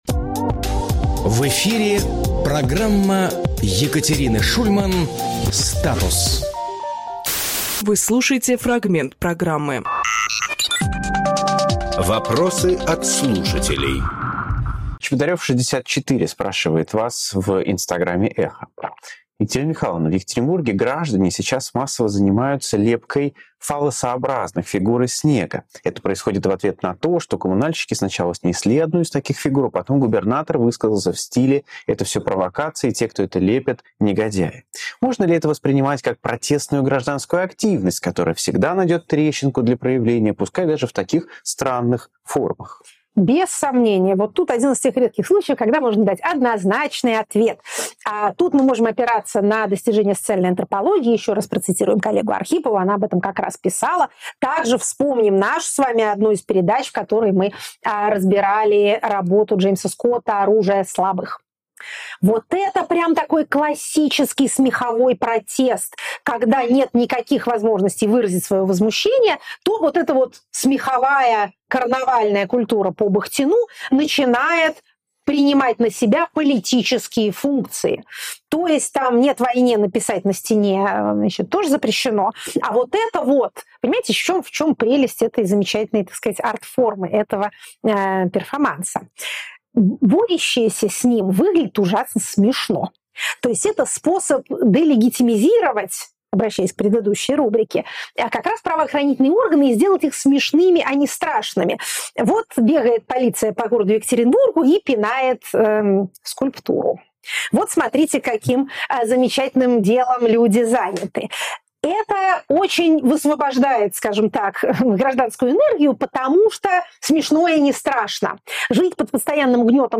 Фрагмент эфира от 7 ноября.